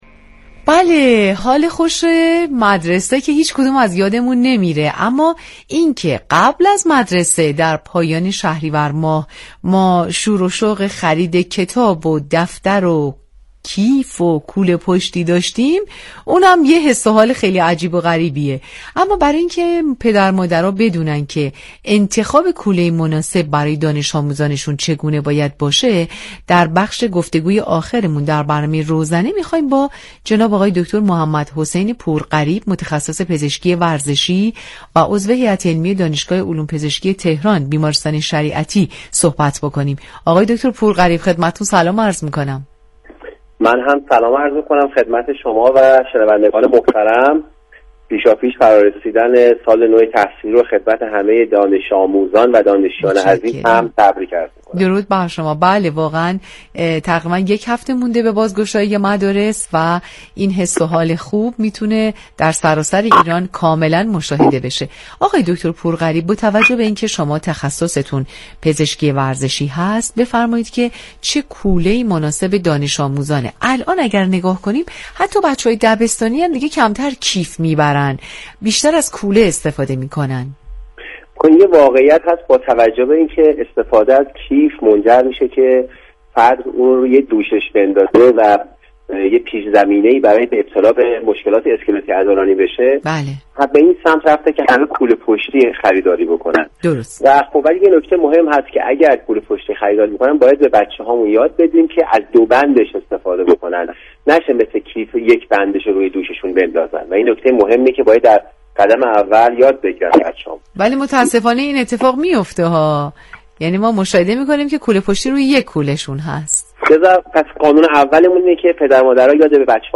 متخصص پزشکی ورزشی